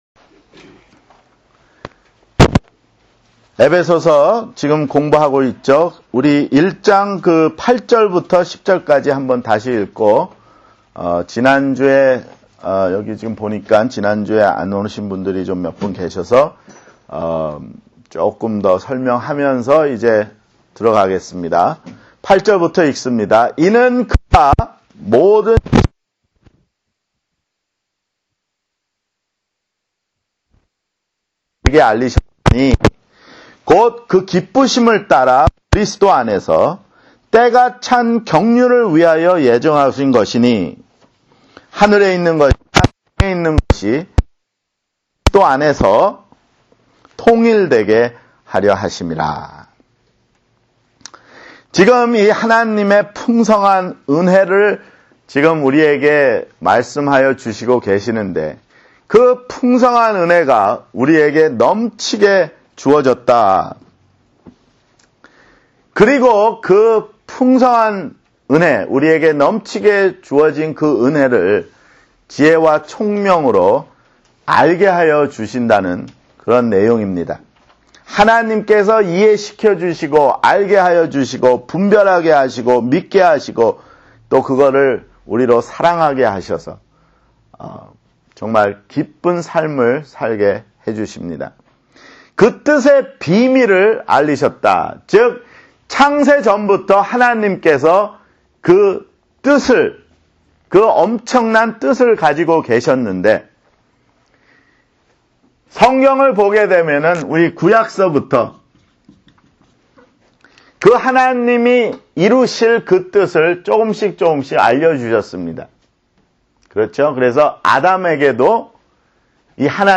[성경공부] 에베소서 (15)